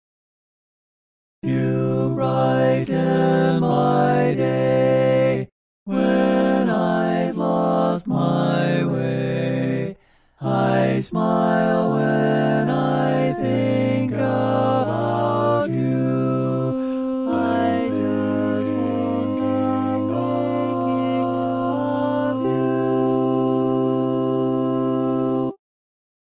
Key written in: C Major
Type: Barbershop